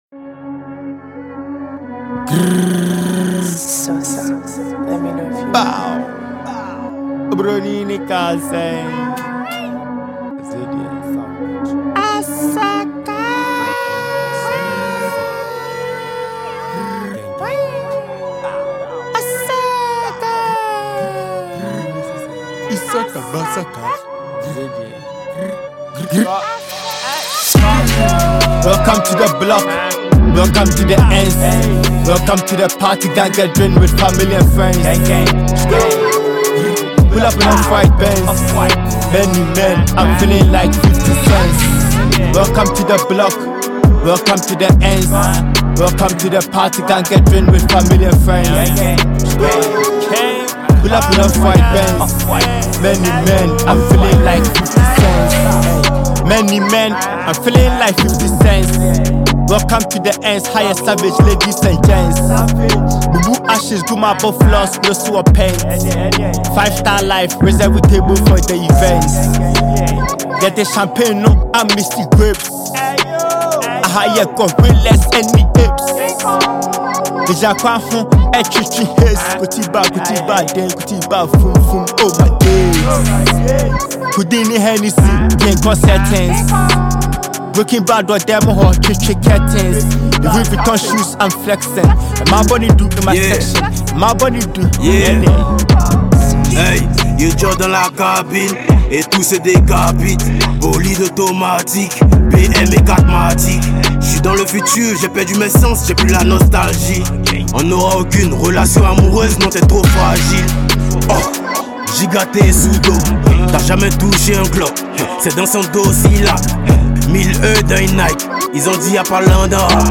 Ghanaian rapper and songwriter